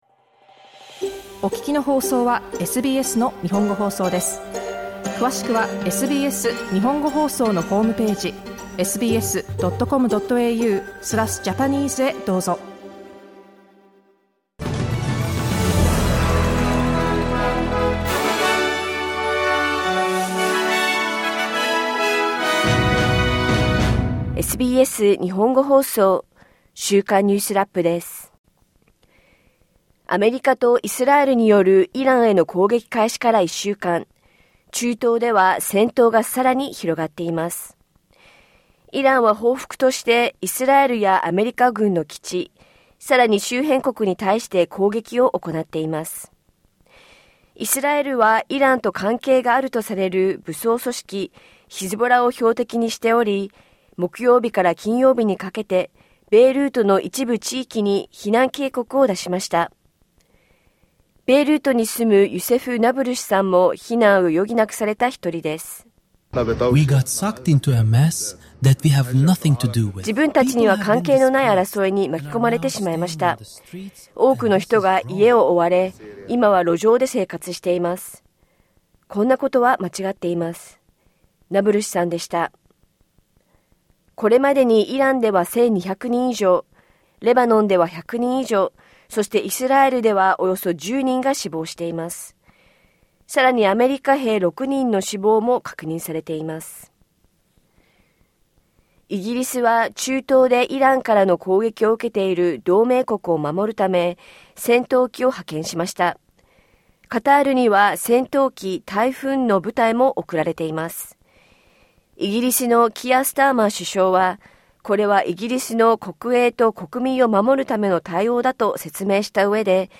SBS Japanese Weekly News Wrap Saturday 7 March